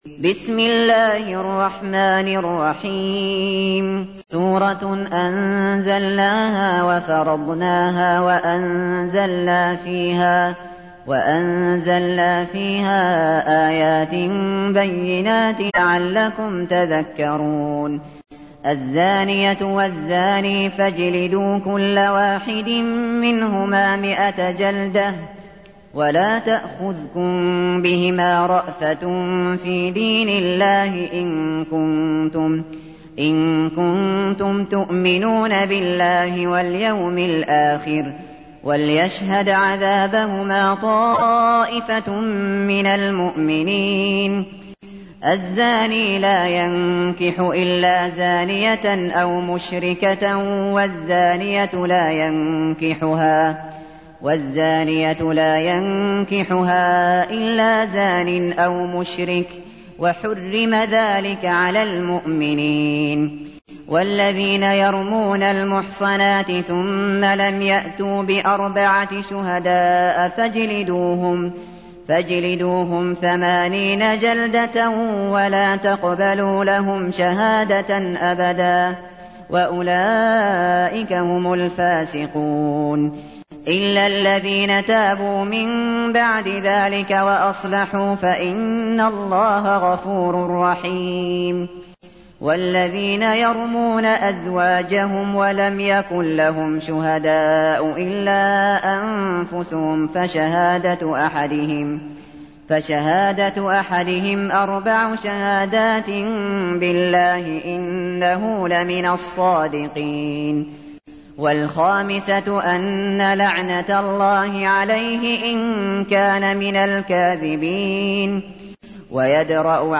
تحميل : 24. سورة النور / القارئ الشاطري / القرآن الكريم / موقع يا حسين